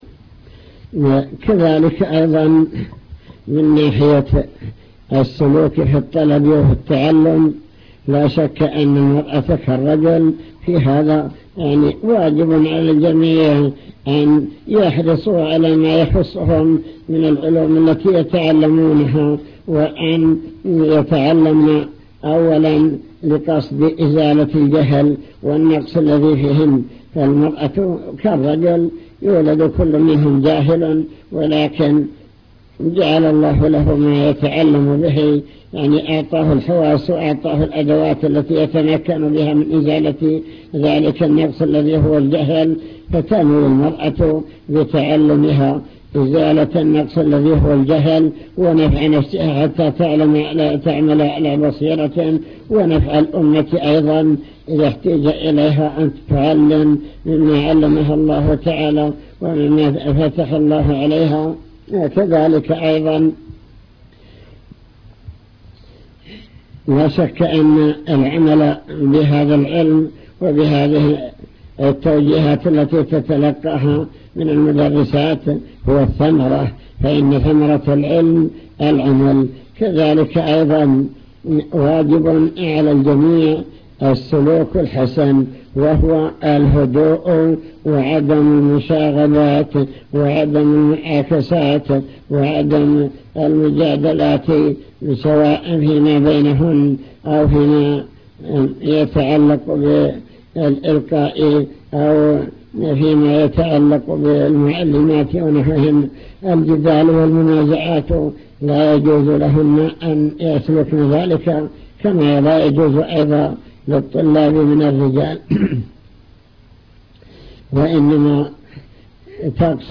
المكتبة الصوتية  تسجيلات - محاضرات ودروس  محاضرة بكلية البنات